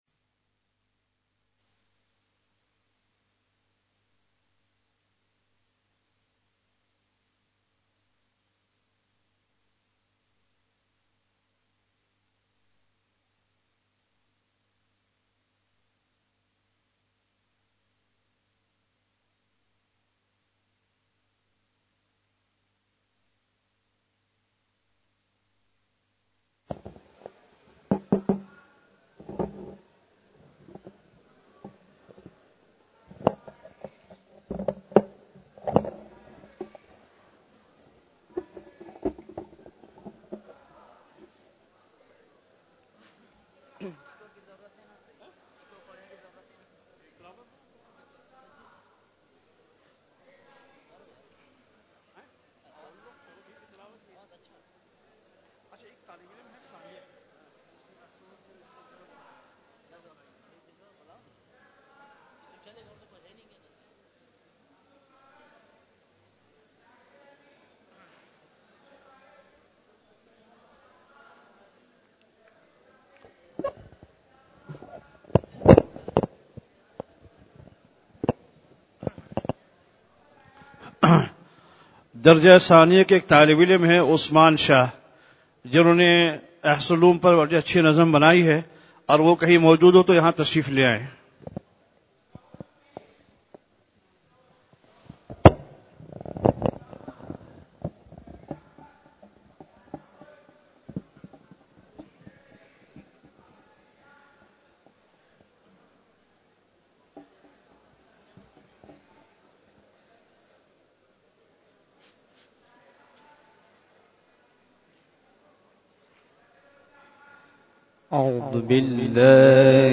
khatam e bukhari sharif 04 February 2023 (12 Rajab 1444HJ) Saturday